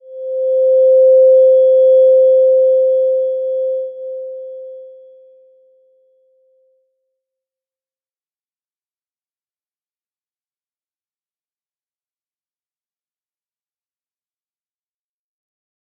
Slow-Distant-Chime-C5-p.wav